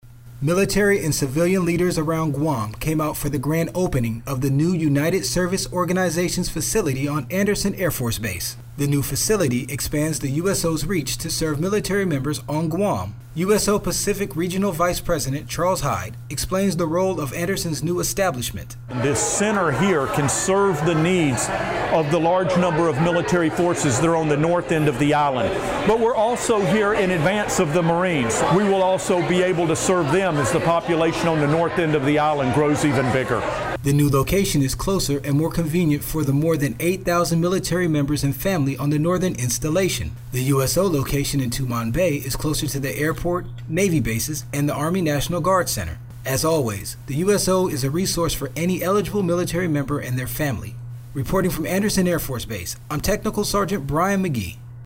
is on island with the report...